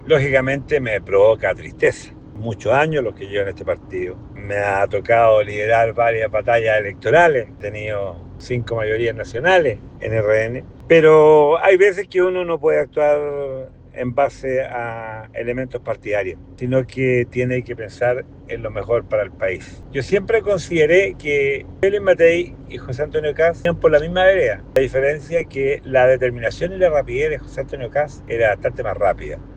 Jaime Veloso, alcalde de Tucapel, explicó a Radio Bío Bío su descuelgue hacia la campaña de Evelyn Matthei, asegurando que la carta de Republicanos, José Antonio Kast, tiene una “determinación más rápida”.